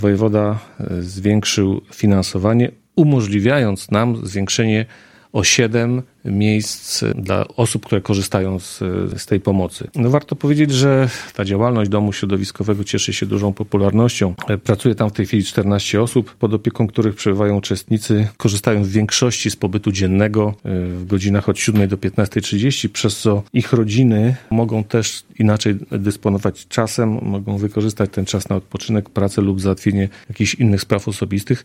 – Ze względu na duże zainteresowanie tego typu pomocą, wystąpiliśmy do wojewody o zwiększenie liczby miejsc w domu. – mówi Mirosław Hołubowicz – zastępca prezydenta Ełku.